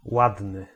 w ł
ładny way